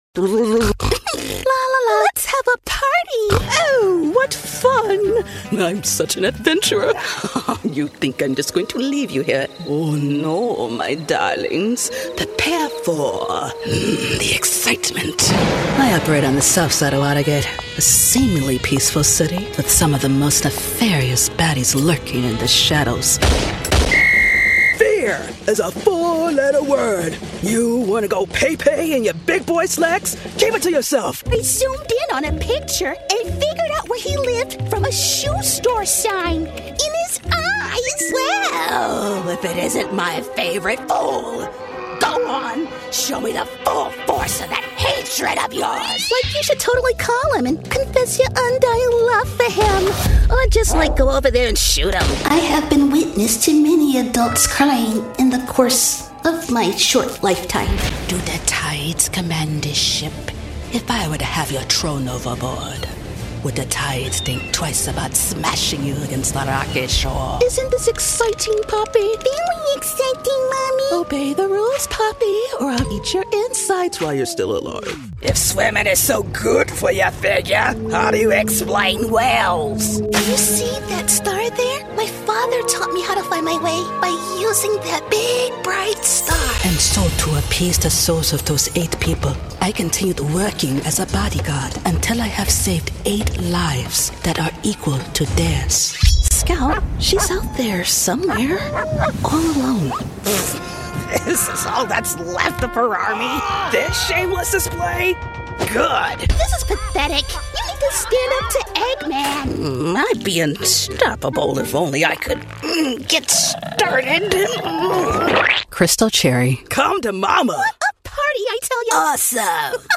Female
Character, Confident, Corporate, Engaging, Friendly, Natural, Smooth, Warm, Versatile
Coaches and clients have described my voice as warm, full of smile, and versatile.
HOME_STUDIO_-_SENNHEISER_MKH_416_Demo.mp3